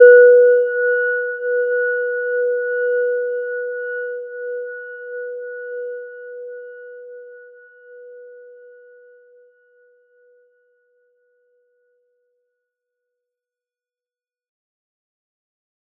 Gentle-Metallic-1-B4-mf.wav